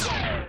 weapon_energy_beam_003_close.wav